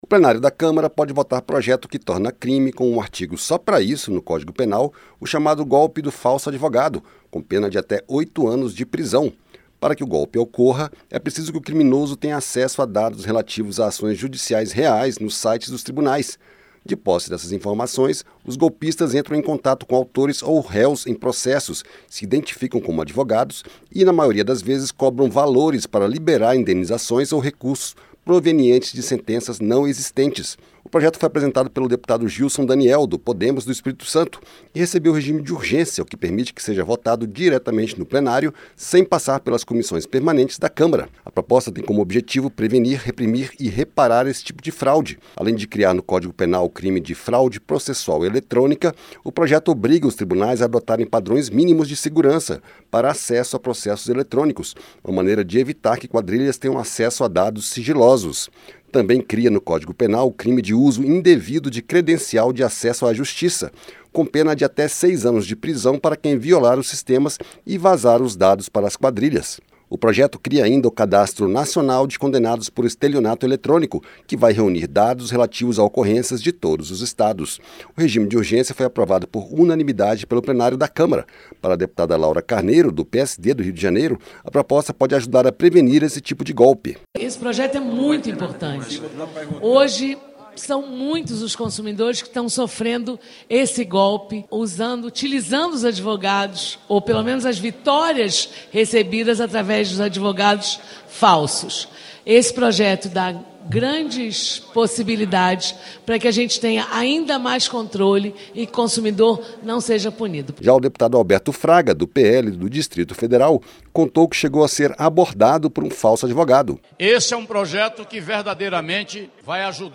PLENÁRIO PODE VOTAR PROJETO QUE TORNA CRIME O GOLPE DO FALSO ADVOGADO, COM EXIGÊNCIAS DE NORMAIS MÍNIMAS DE SEGURANÇA ELETRÔNICA PELOS TRIBUNAIS. O REPÓRTER